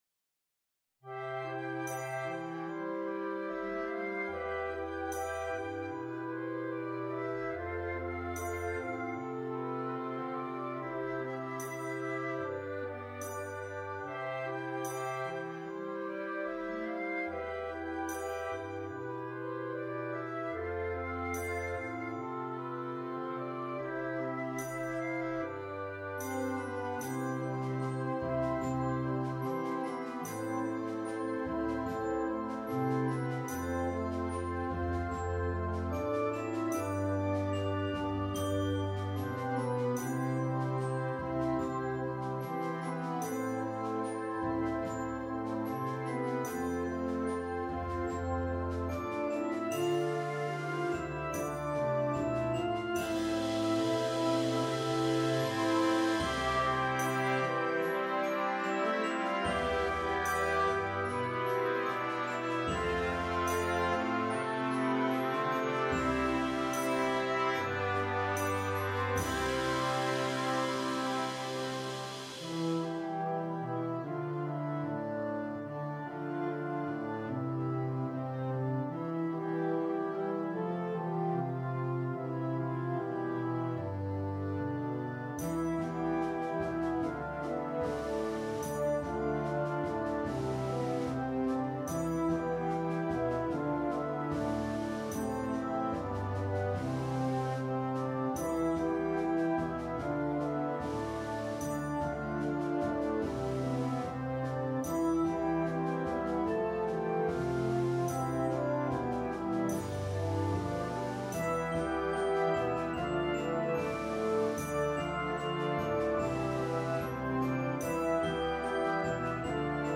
An integrated bass line.
Beautiful melodies and counter melodies.
During the song you can hear this conflict.